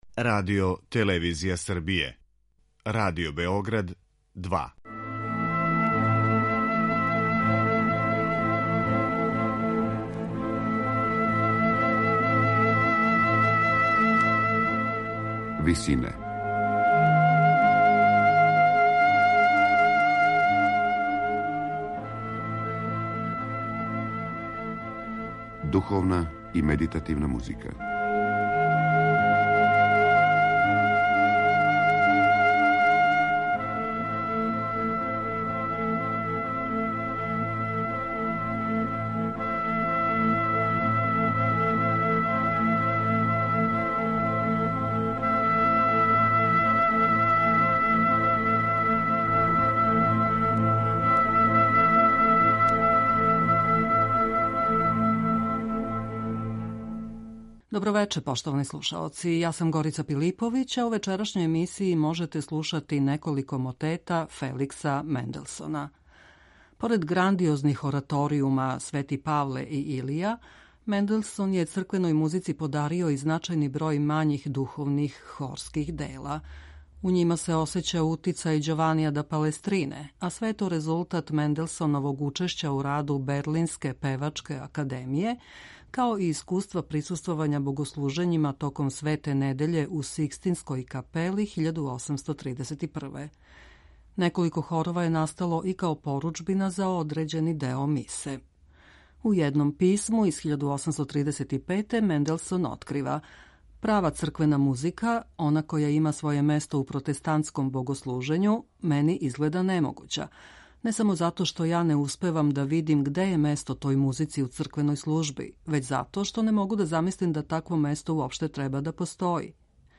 медитативне и духовне композиције
Поред грандиозних ораторијума Св.Павле и Илија Менделсон је црквеној музици подарио и значајан број мањих духовних хорских дела.